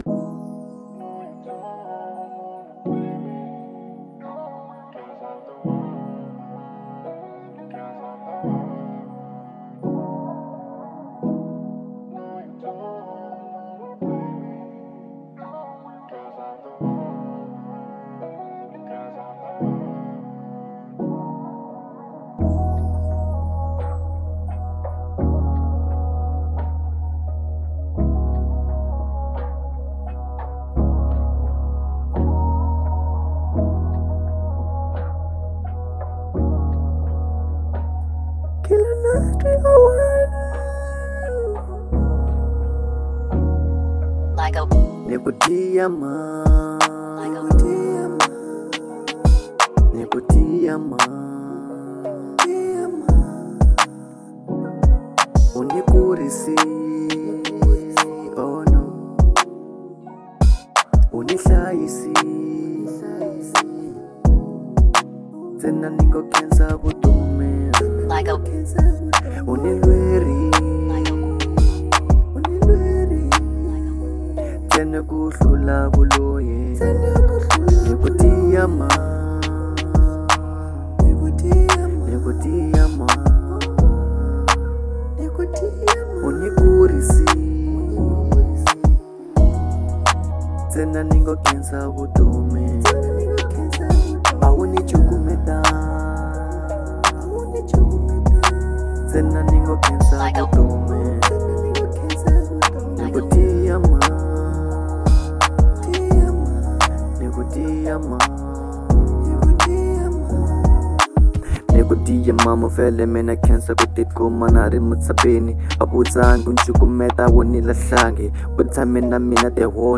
04:15 Genre : Trap Size